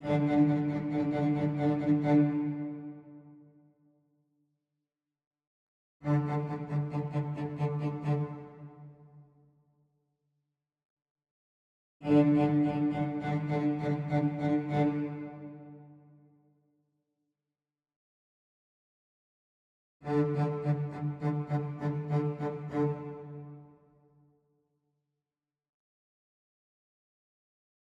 I did a little test with short notes and I must say two things:
I recorded the little test and in the second repetition I brought the velocity almost at the maximum level, by doing this I felt the notes became more distinct that in the first repetition. You will hear v 1.0.1 before and 1.1 after.
In the 002 MP3 the attack knob is at 0 and the other settings are the same for 1.0.1 and 1.1.